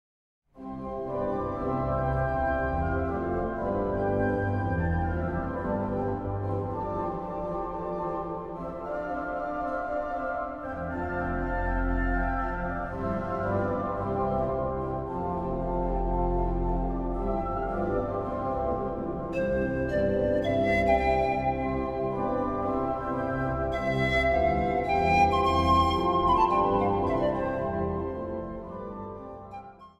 Instrumentaal | Panfluit